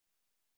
♪ kułisu